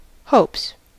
Uttal
Synonymer hopeth Uttal US Okänd accent: IPA : /hoʊps/ IPA : /həʊps/ Ordet hittades på dessa språk: engelska Ingen översättning hittades i den valda målspråket.